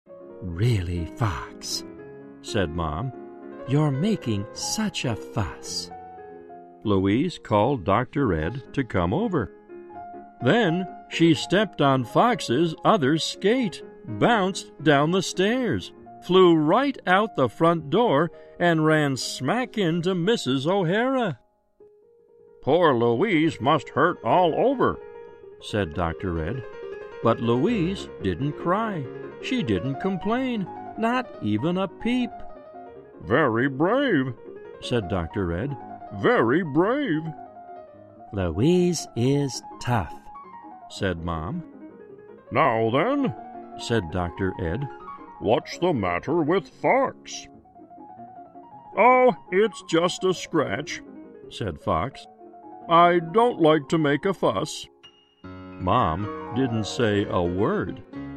在线英语听力室小狐外传 第59期:溜冰鞋的听力文件下载,《小狐外传》是双语有声读物下面的子栏目，非常适合英语学习爱好者进行细心品读。故事内容讲述了一个小男生在学校、家庭里的各种角色转换以及生活中的趣事。